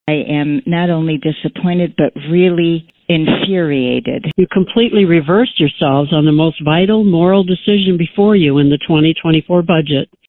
KALAMAZOO, MI (WKZO AM/FM) – Abortion was on the national agenda on election day in several states and Wednesday night it became a point of contention at the Kalamazoo County board meeting.
Opponents to funding a YWCA program that provides reproductive healthcare to the poor, expressed their anger that it ended up in the budget, despite public opposition.